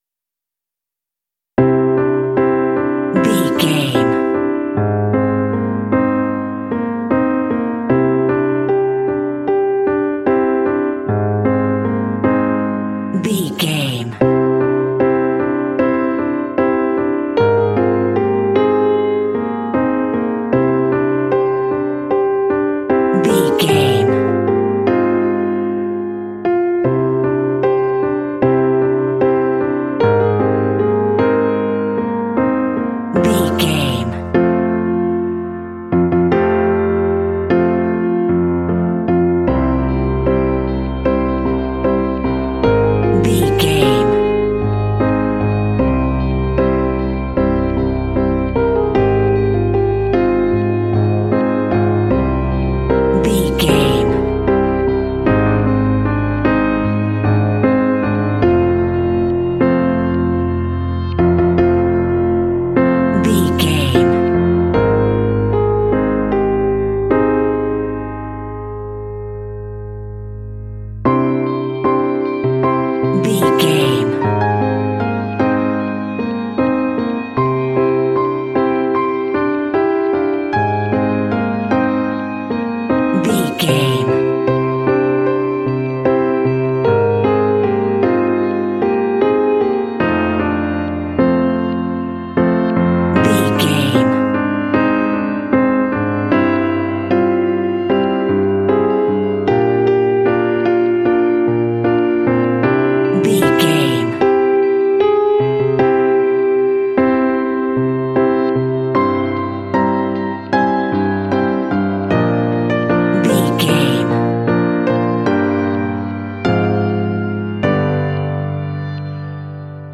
Aeolian/Minor
melancholy
calm
reflective
dramatic
piano
electric guitar